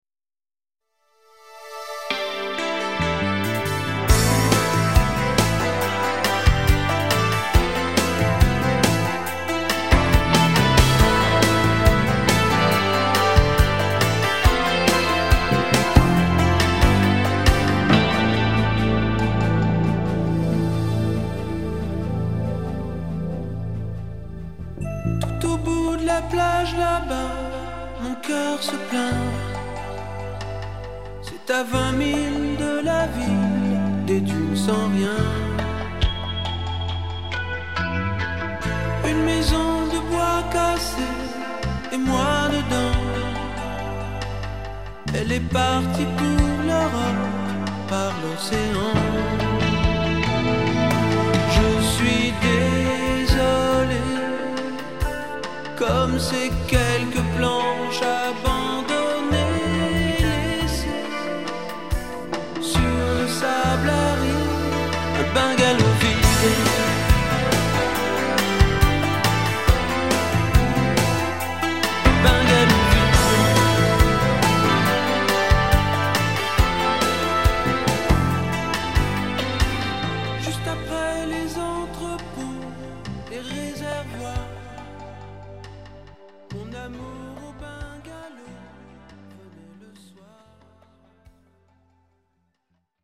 tonalité LA mineur